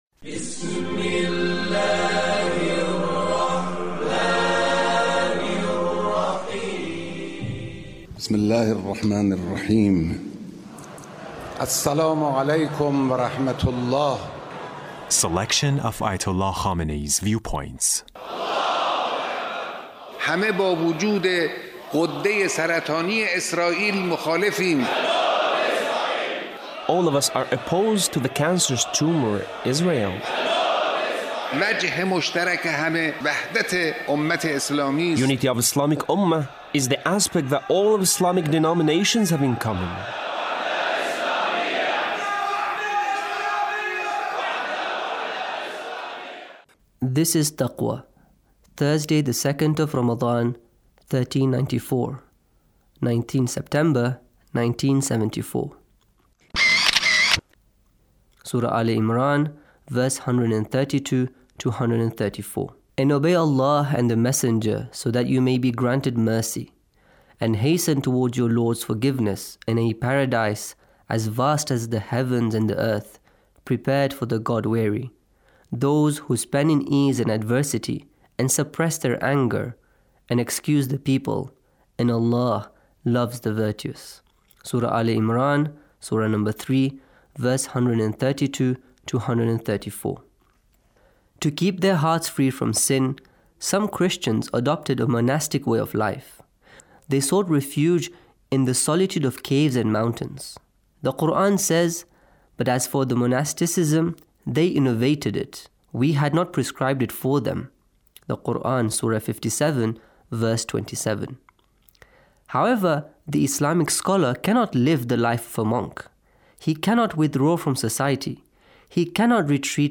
Leader's Speech (1519)
Leader's Speech on Taqwa